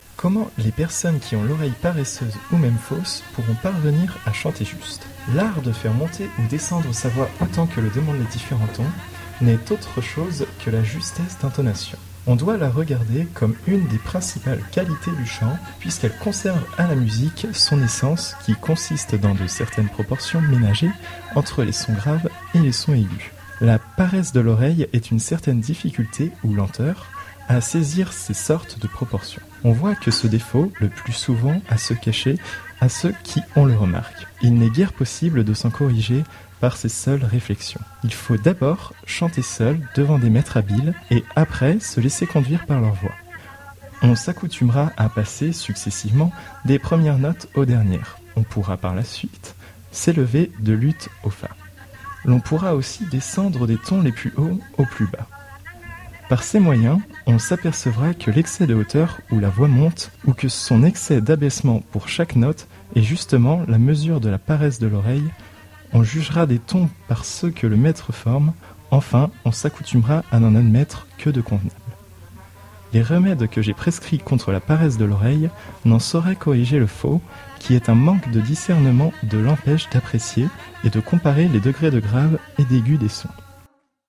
Avec les enregistrements en question, nous avons concocté des pièces sonores, avec adjonction de sons, musiques ou extraits de films… Enjoy!
Leçon de chant